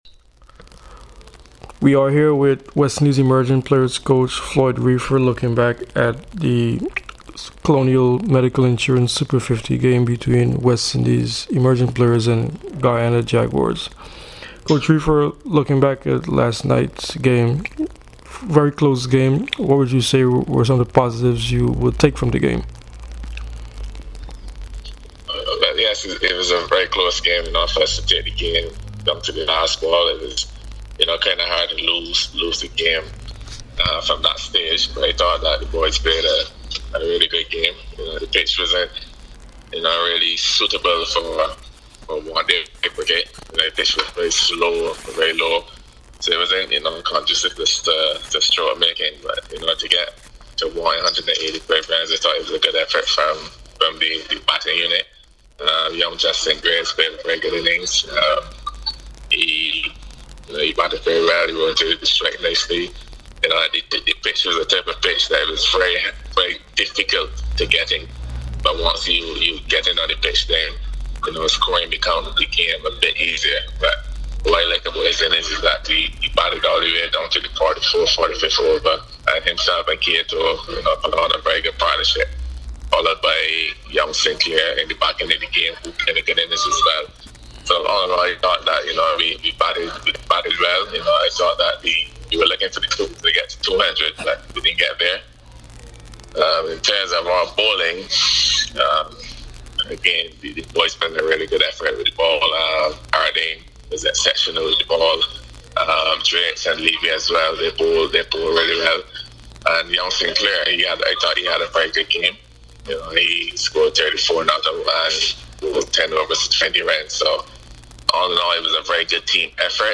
Floyd Reifer spoke to CWI Media and press after Zone “B” in the Colonial Medical Insurance Super50 Cup on Thursday at Queen's Park Oval and Brian Lara Cricket Academy